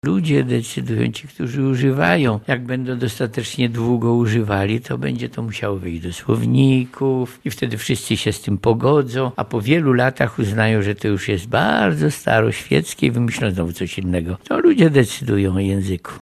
O tym, że mówienie jest sztuką opowiadał profesor Jerzy Bralczyk podczas spotkania w Wojewódzkiej Bibliotece Publicznej im. Hieronima Łopacińskiego w Lublinie.